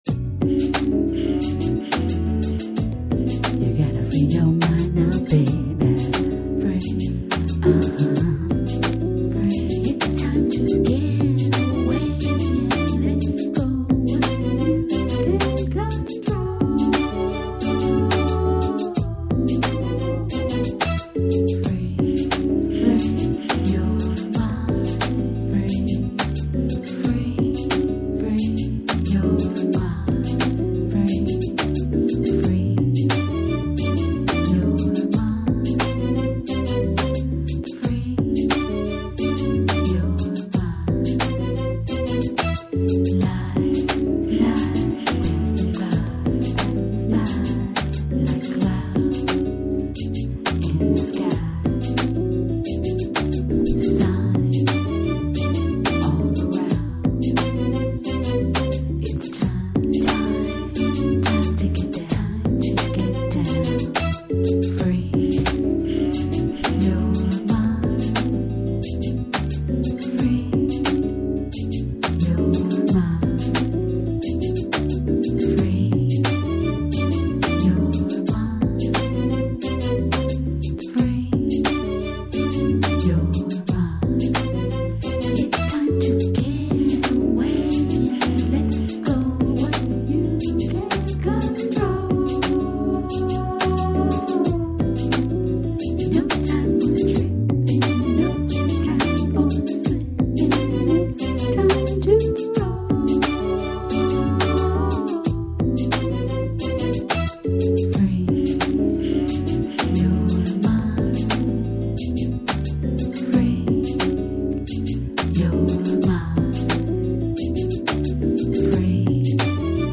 cover of a jazzy r&b track